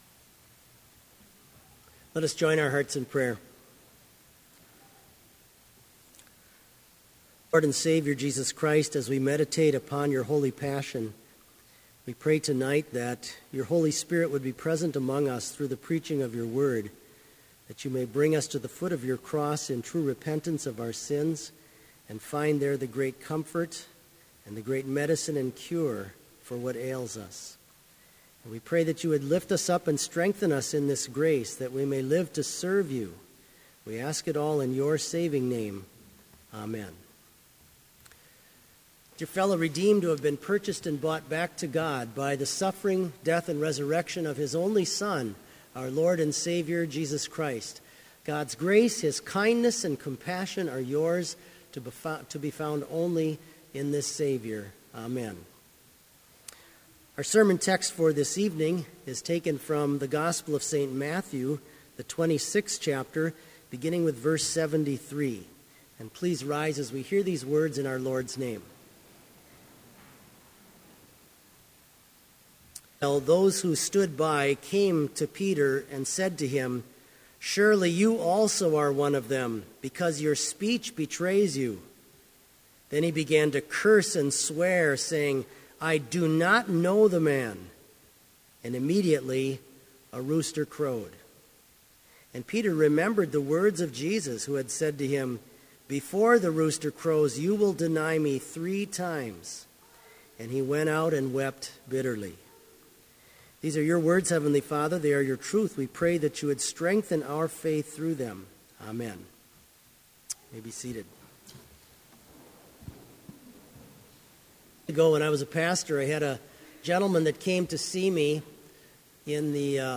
Sermon audio for Lenten Vespers - February 24, 2016